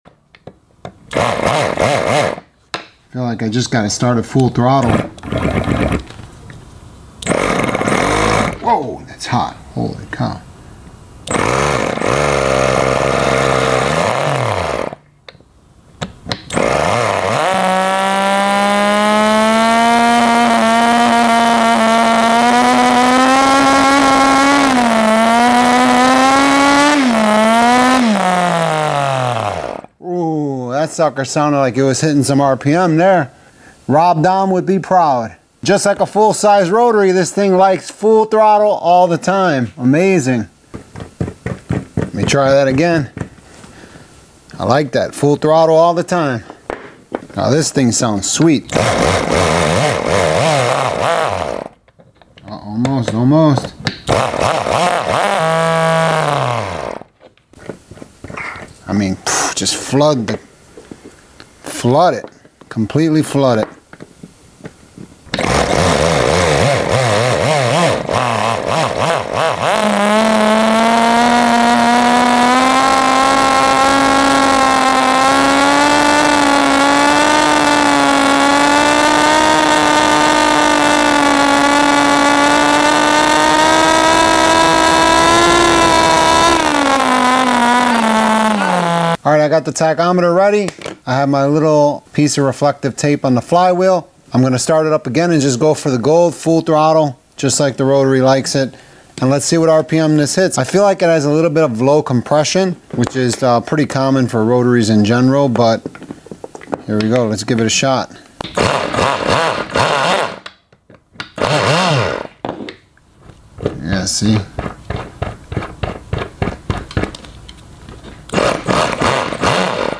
Worlds Smallest Rotary Engine (30.000 RPM)